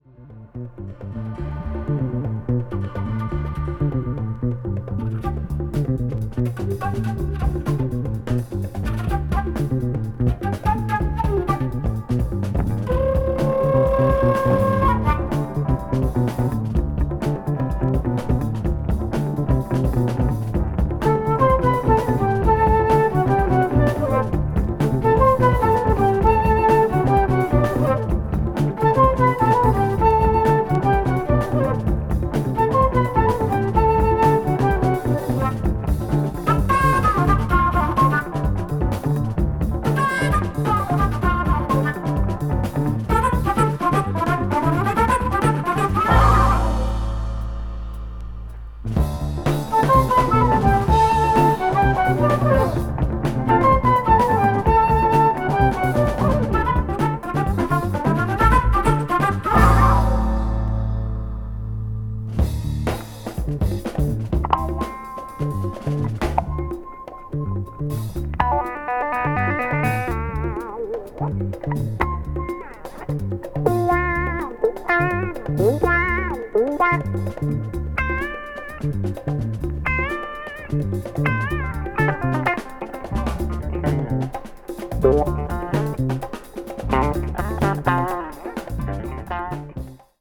crossover   ethnic jazz   jazz funk   jazz groove